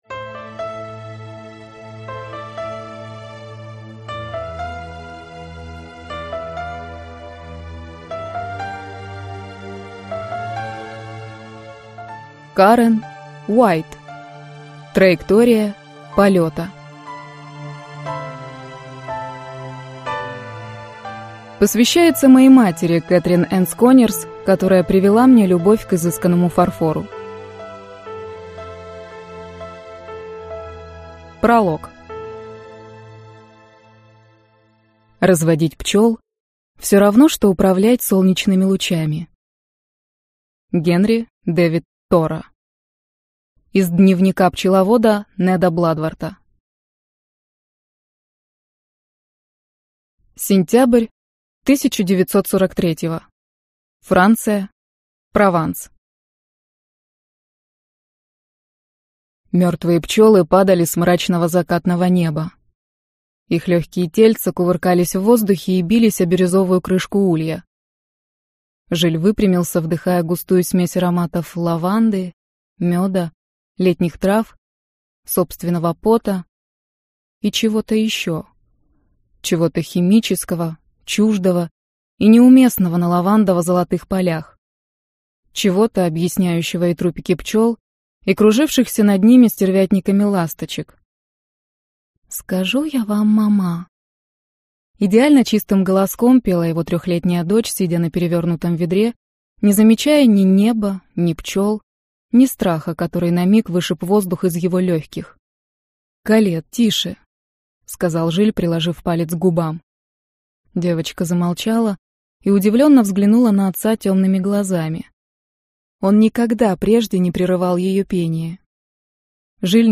Аудиокнига Траектория полета - купить, скачать и слушать онлайн | КнигоПоиск